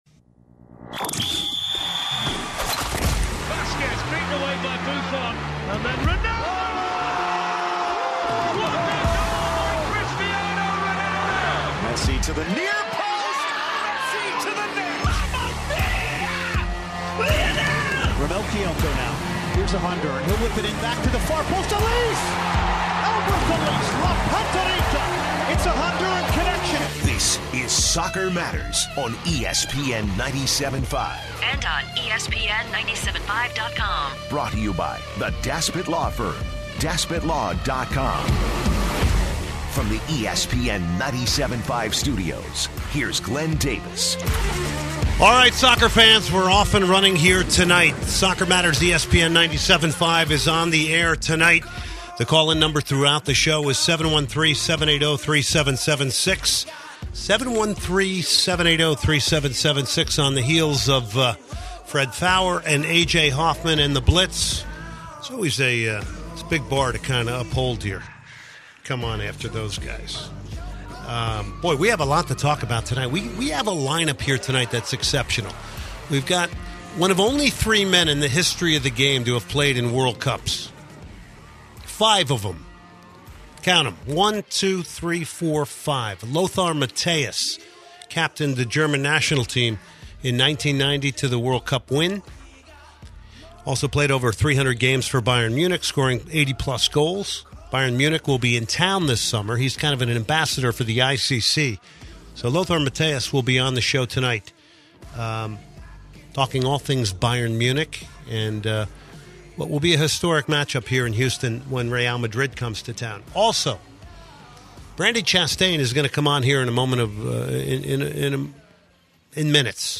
retired soccer player who is a 2-time FIFA World Cup Women’s champion for the U.S. She gives her thoughts on the upcoming Women’s World Cup tournament this summer in France.